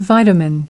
29. vitamin (n) /ˈvaɪtəmɪn/: vi-ta-min (chất hữu cơ có trong thức ăn)